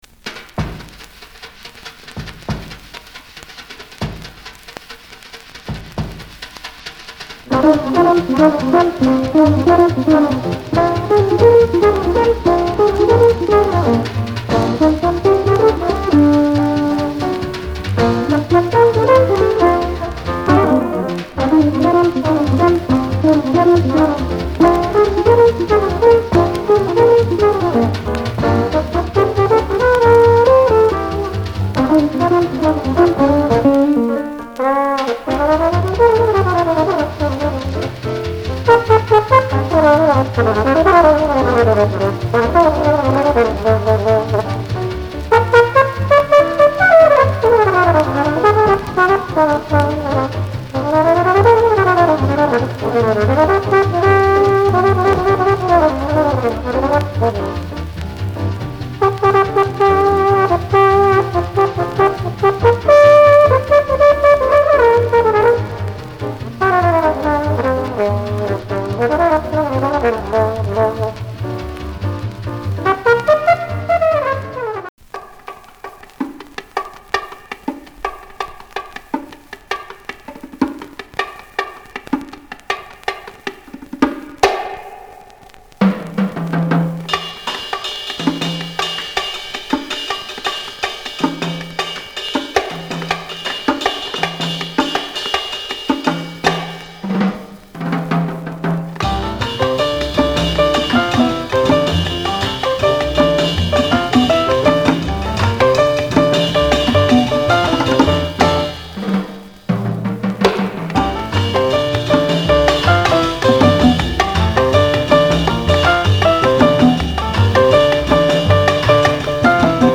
discription:Mono黄色Tpラベル両溝